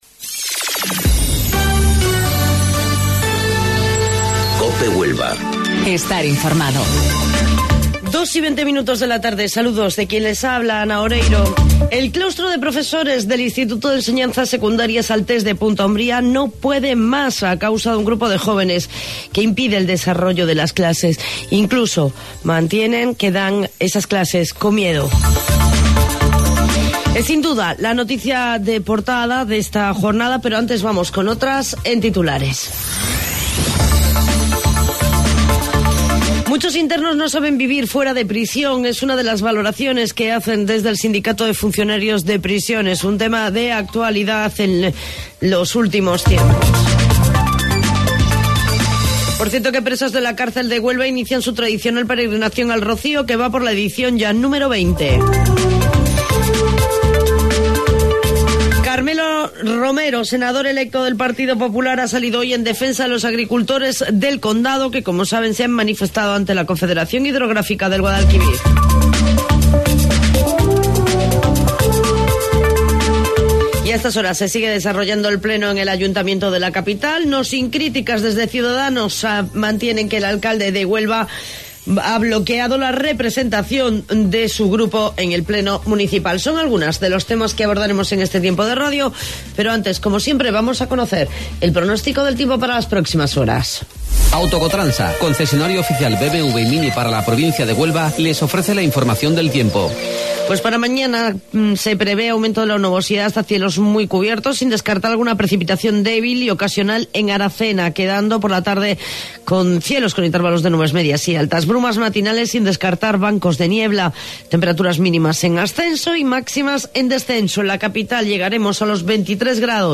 AUDIO: Informativo Local 14:20 del 7 de Mayo